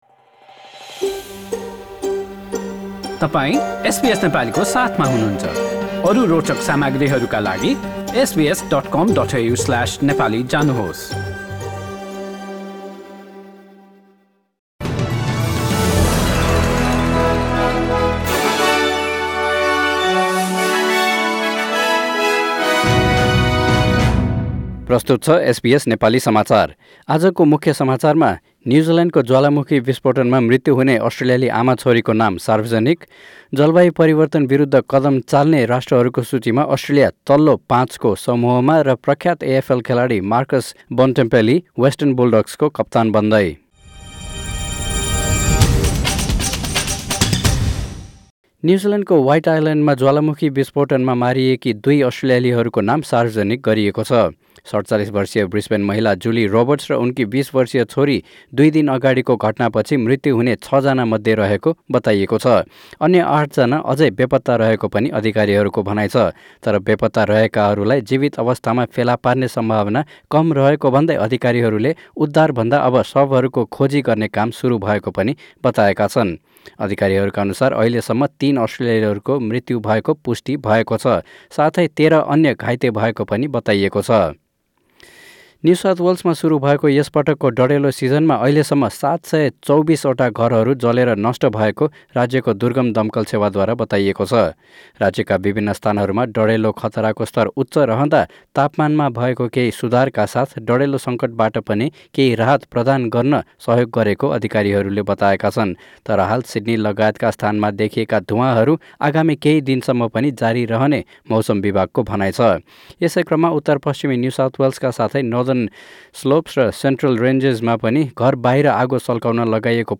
SBS Nepali Australia News: Wednesday 11 December 2019
Listen to the latest news headlines in Australia from SBS Nepali radio.